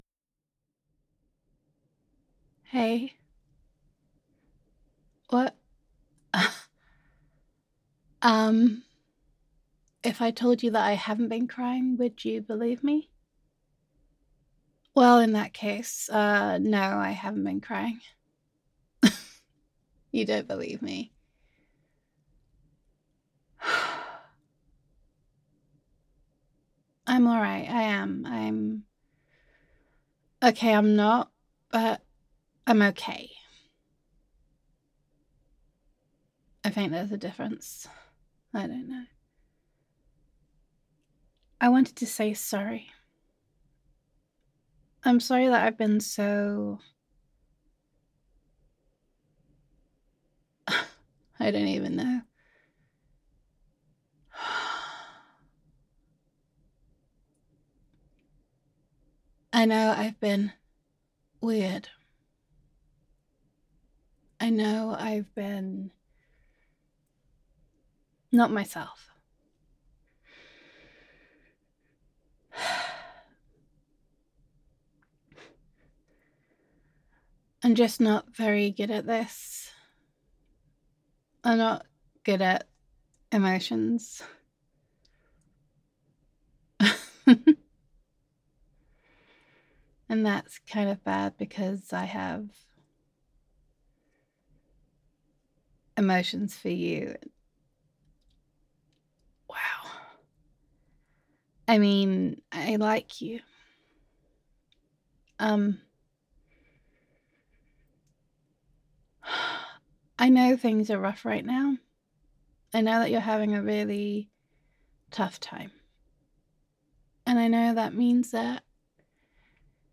[F4A] Solid Gold Sweetheart [Life Can Be Rough][I Really, Really Like You][Appreciation][Falling for You][Honesty][Insecurity and Doubt][Gender Neutral][Comforting Your Girlfriend in Tough Times]